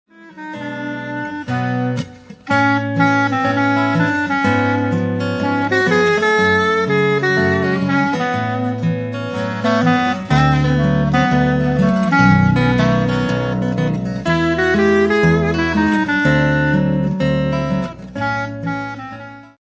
chitarra acustica